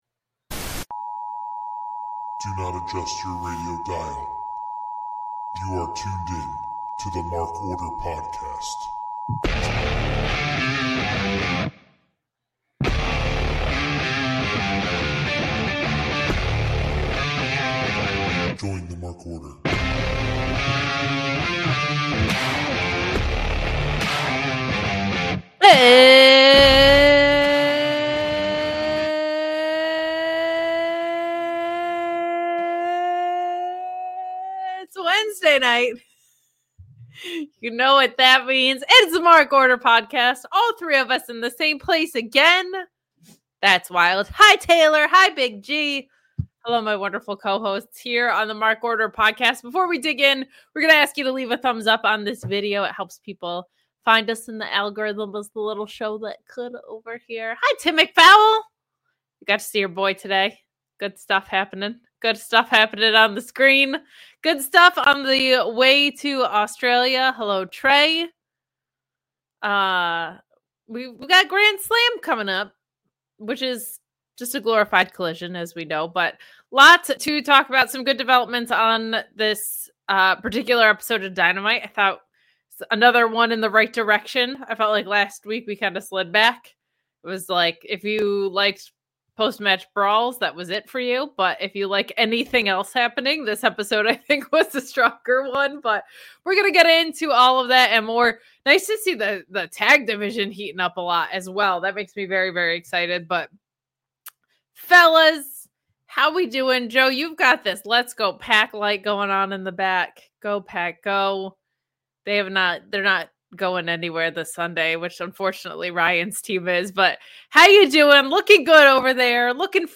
The full group is in the house tonight- AGAIN! The gang talks about a fun episode of Dynamite.